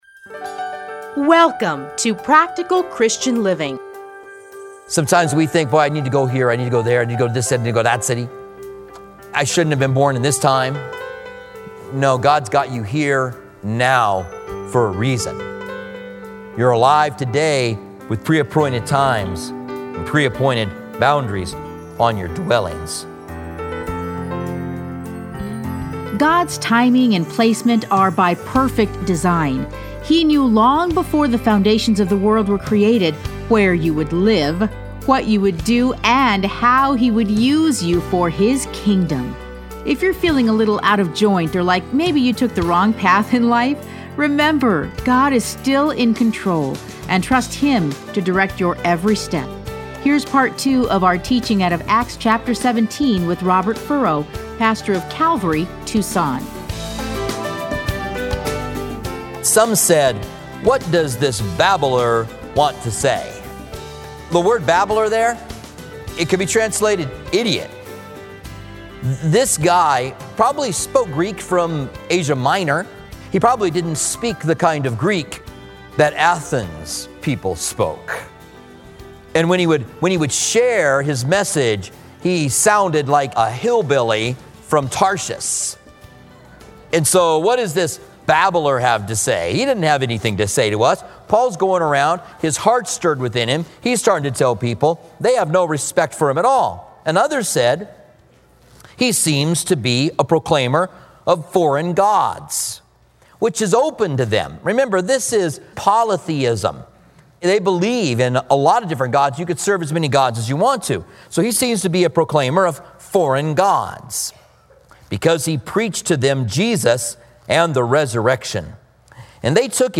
Listen to a teaching from Acts 17.